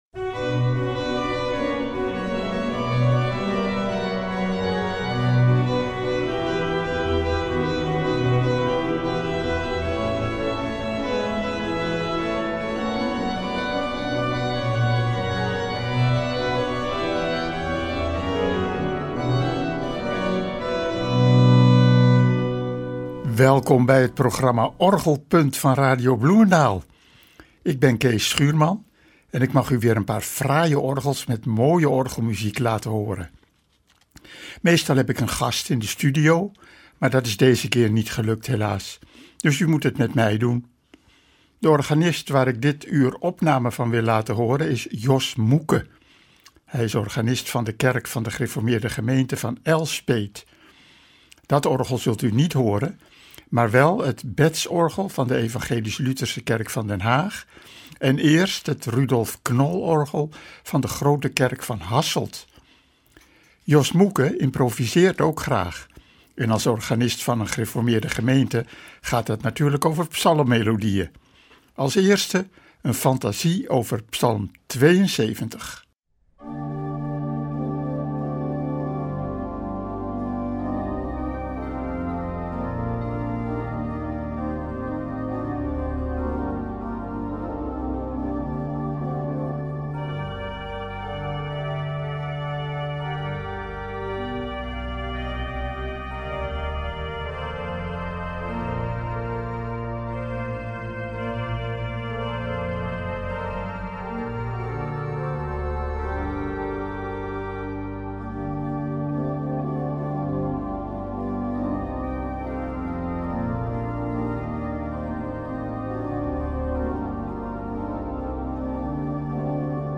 Naast mooi uitgevoerde werken staan er ook de nodige psalmimprovisaties op de cd die de moeite waard zijn om te horen.